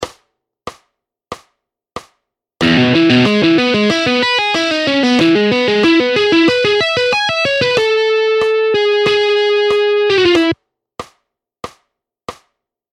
By combining the use of the pick and fingers, hybrid picking enables players to achieve a rich and dynamic sound that can elevate their playing to new heights.
Hybrid-Picking-Exercise-1.mp3